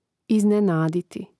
iznenáditi iznenaditi